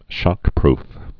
(shŏkprf)